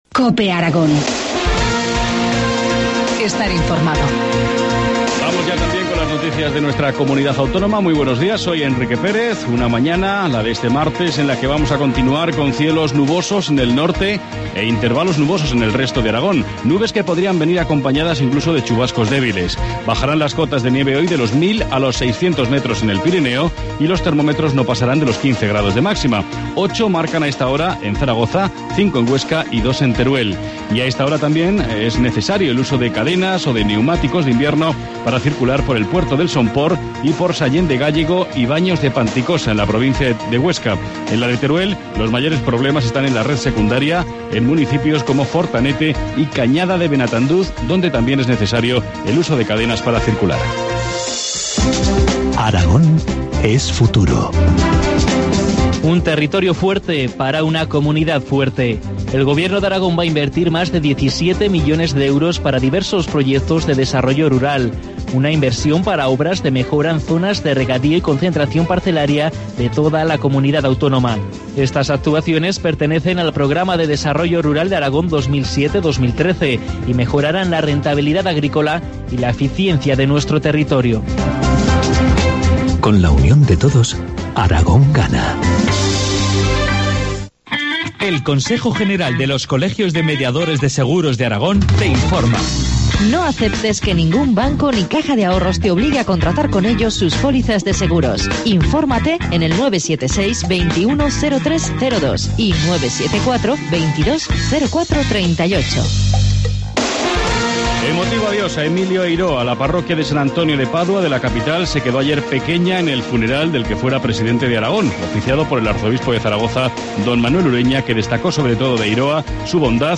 Informativo matinal, martes 12 de marzo, 8.25 horas